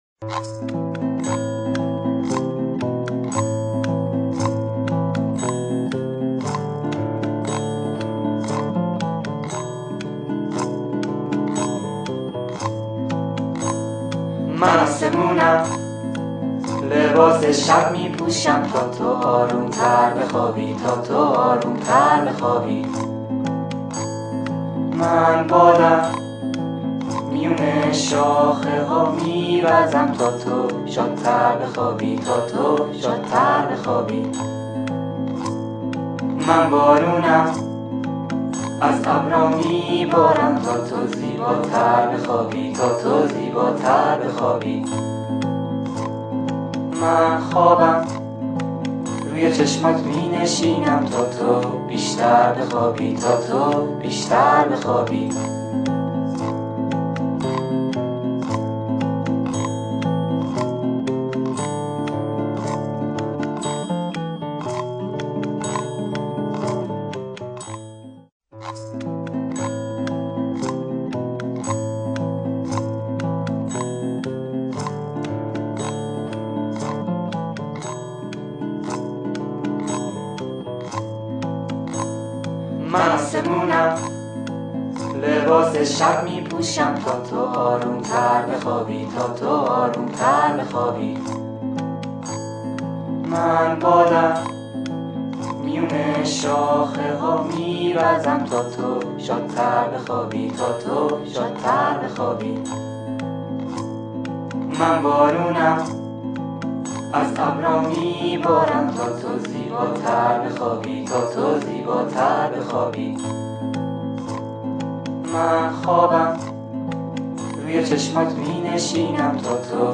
• لالایی آهنگ لالایی